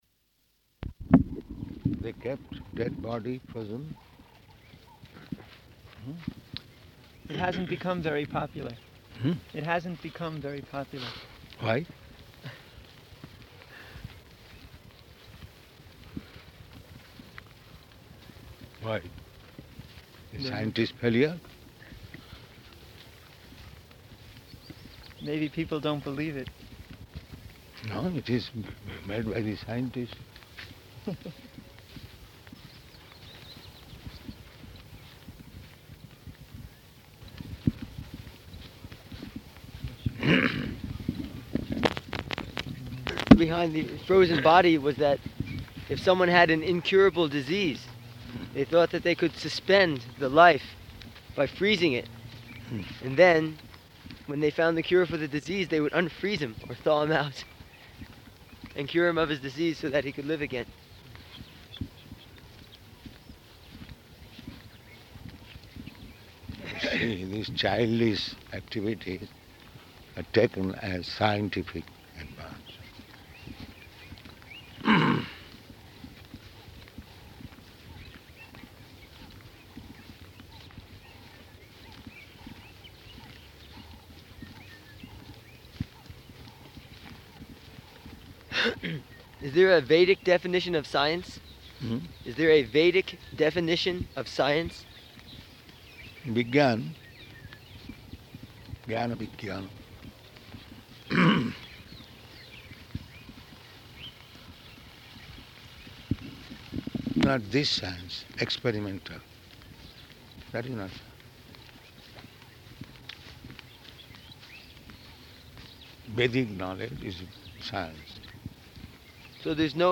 Morning Walk --:-- --:-- Type: Walk Dated: October 20th 1975 Location: Johannesburg Audio file: 751020MW.JOH.mp3 Prabhupāda: They kept dead body frozen?